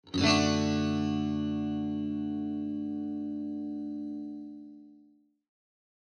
Clean Sound Guitar
Cleanジャラーン(D) 118.27 KB